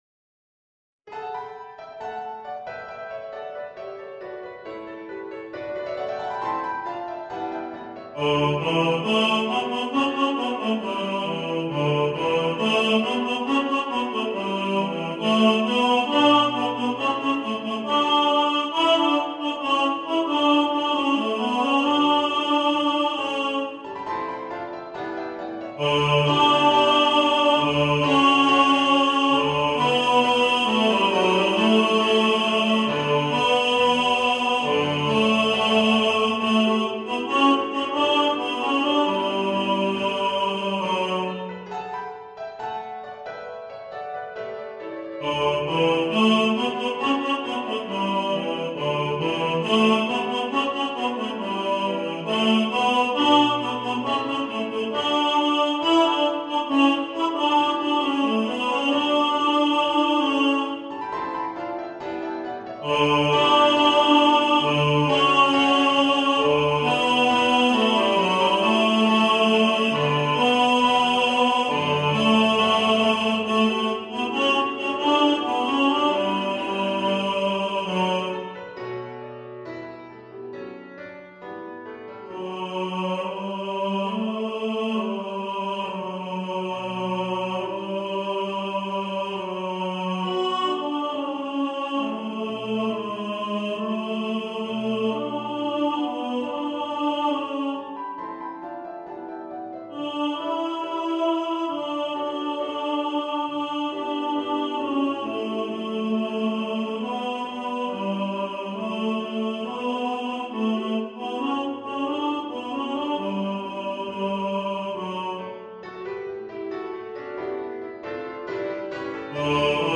Star-Carol-Tenor.mp3